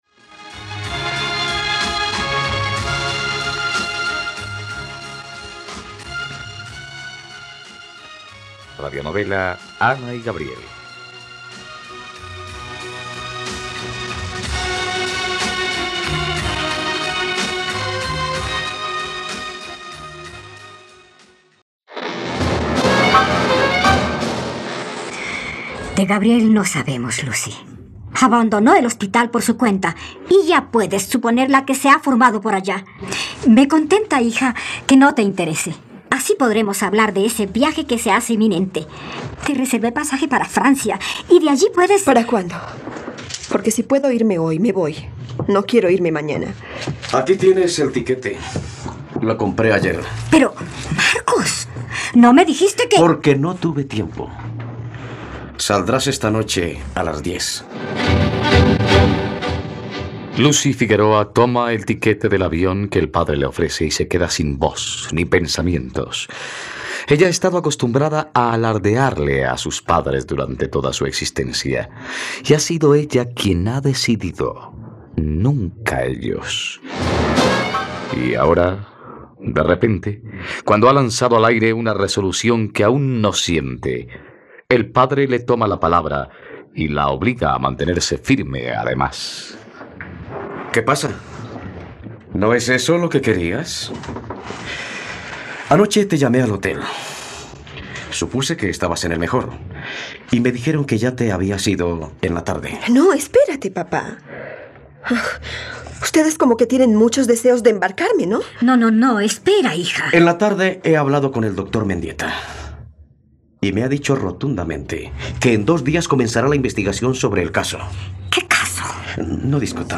..Radionovela. Escucha ahora el capítulo 126 de la historia de amor de Ana y Gabriel en la plataforma de streaming de los colombianos: RTVCPlay.